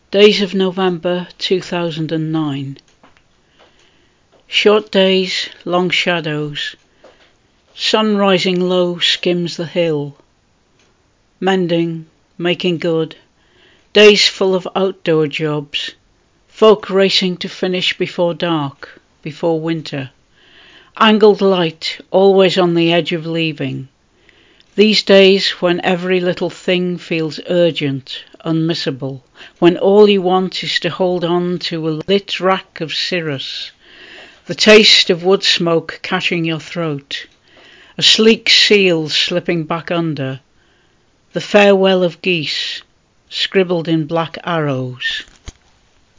Days of November 2009 read by Sheenagh Pugh